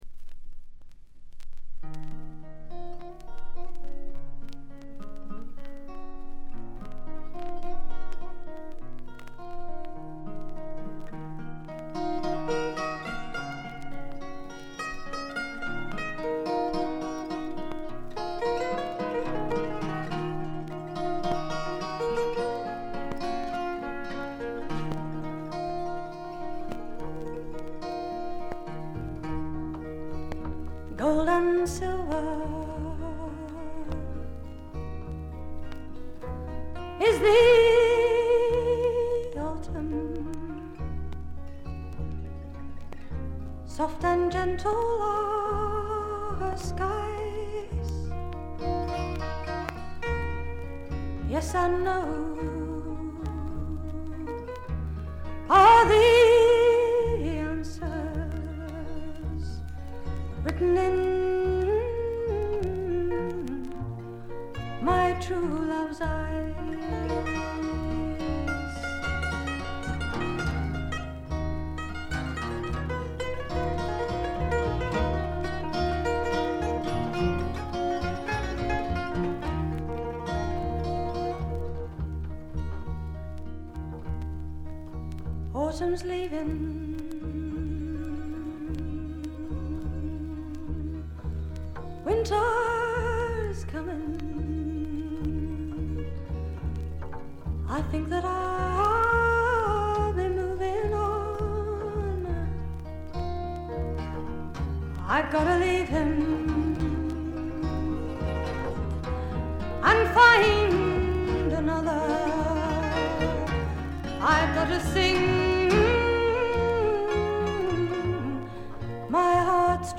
ホーム > レコード：英国 SSW / フォークロック
A1ノイズ多いです。
試聴曲は現品からの取り込み音源です。